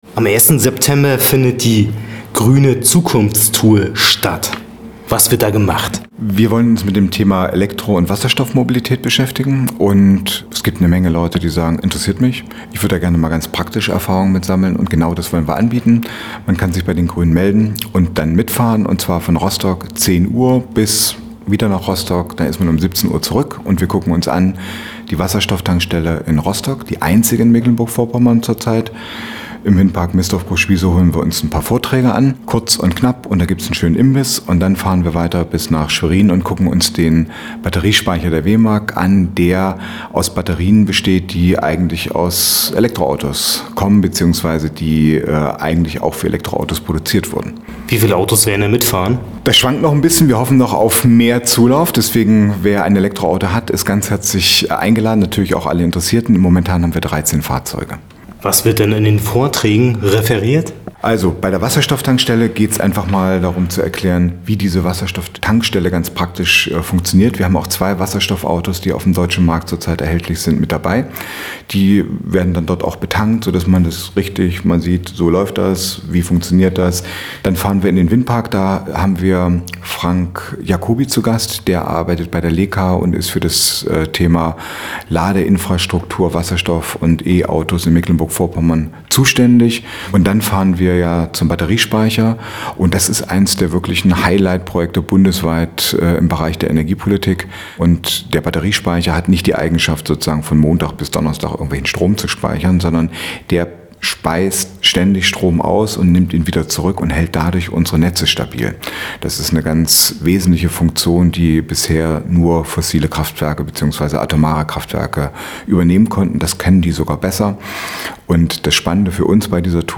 Thematisch geht’s um klimafreundliche Mobilität. Alles weitere erfahrt ihr jetzt im Interview